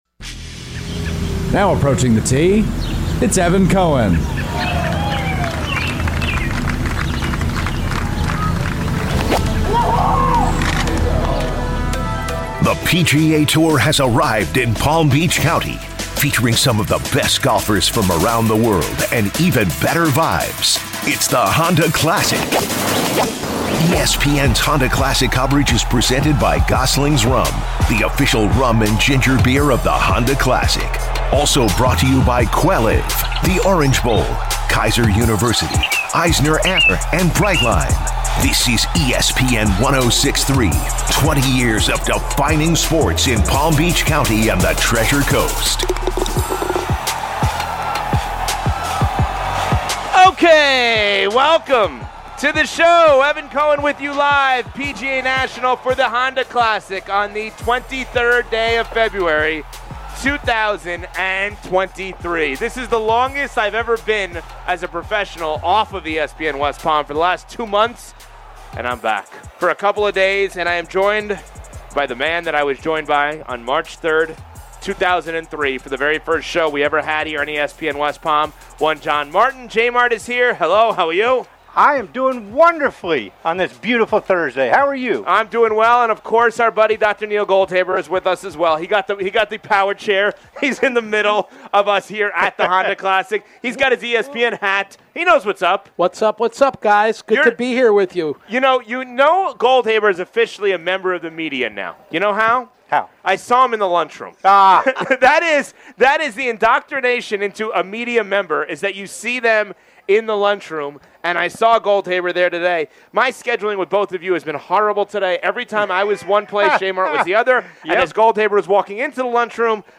Live From The Honda Classic